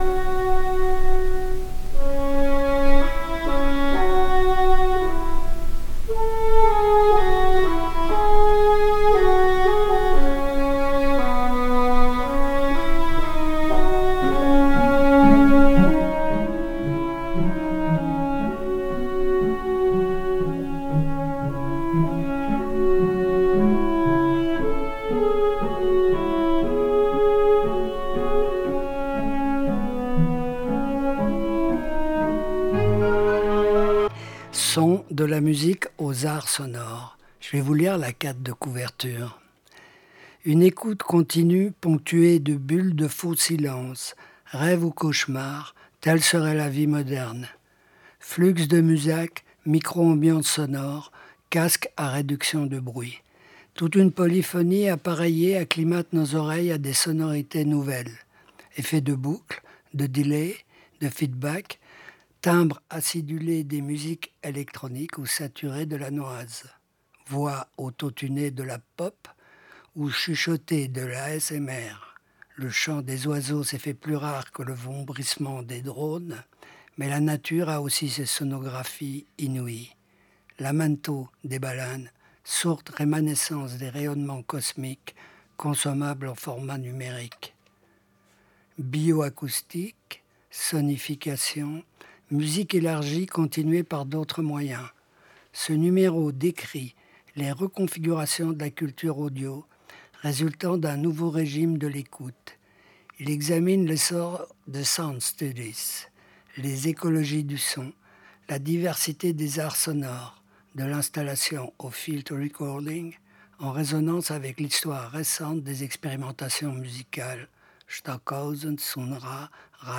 Critique n°927-928 : SONS. De la musique aux arts sonores - Interview